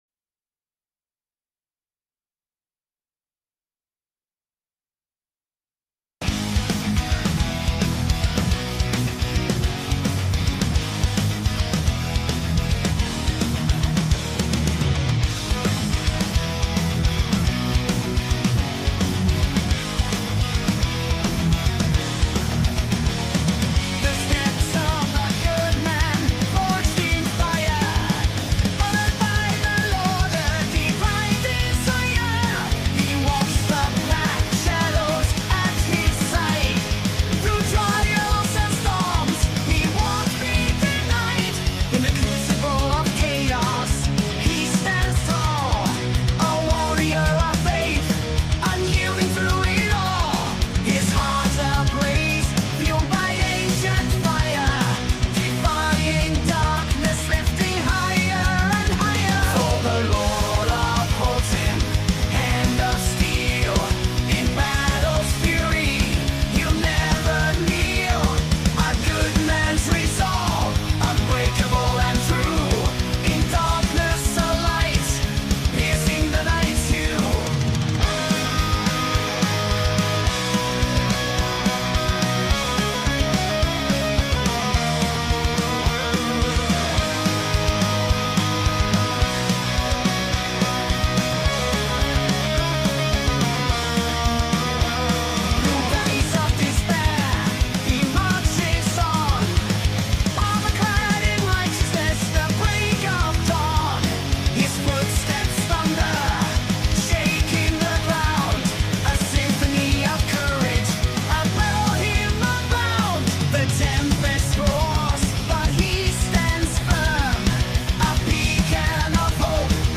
Talk Show Episode, Audio Podcast, Sons of Liberty Radio and Hurricane Helene Conspiracy Theories Or Are The People Wising Up? on , show guests , about Hurricane Helene Conspiracy Theories Or Are The People Wising Up, categorized as Education,History,Military,News,Politics & Government,Religion,Christianity,Society and Culture,Theory & Conspiracy